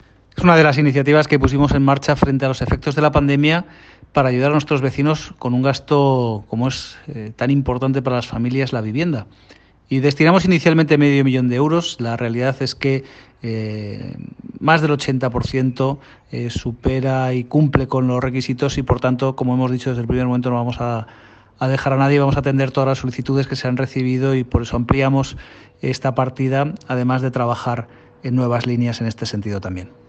Declaraciones del alcalde José de la Uz.
Declaraciones_Jose_de_la_Uz_21_09.m4a